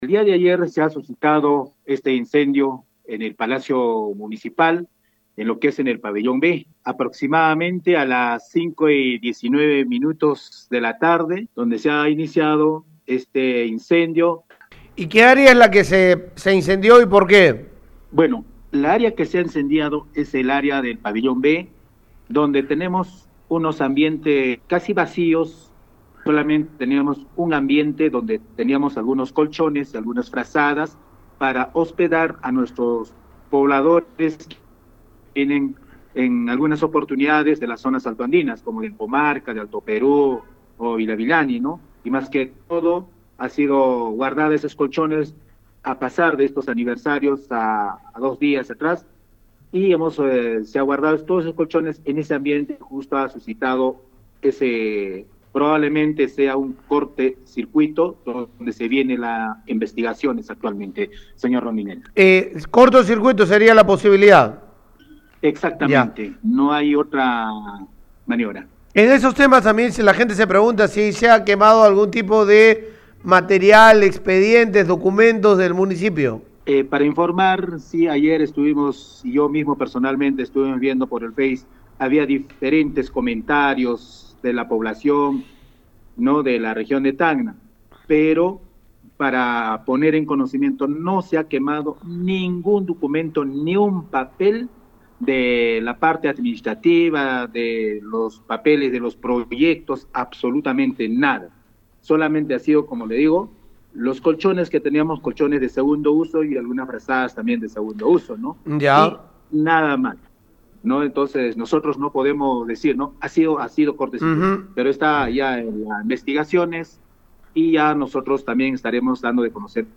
Luego de ocurrir un incendio en instalaciones de la municipalidad distrital de Palca, el alcalde Toribio Onofre Sanga, aseguró a Radio Uno que las instalaciones afectadas albergaban algunos colchones y frazadas que eran empleadas para albergadas para hospedar a población que llegan de zonas altoandinas como Ancomarca, Alto Perú o Vilavilani.